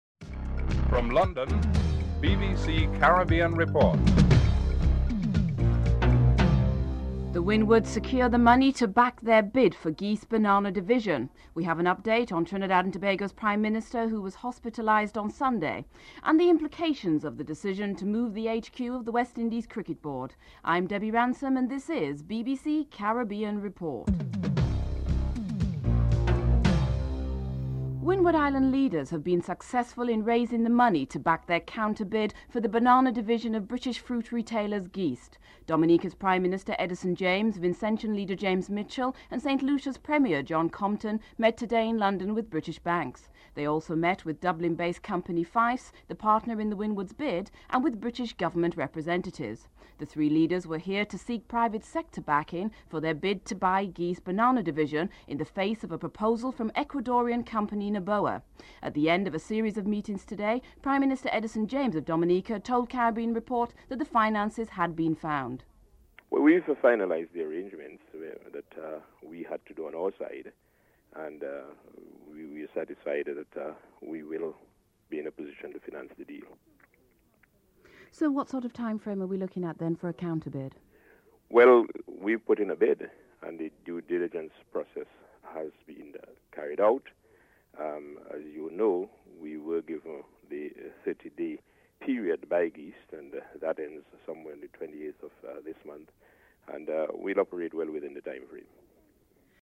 In this report the Windward Islands have been successful in securing funds to back their bid for Geest's banana division. Dominica's Prime Minister Edison James comments on the financing and their chances of obtaining the banana business.
Cricket historian, Dr. Hilary Beckles comments on the relocation of the Board to Antigua.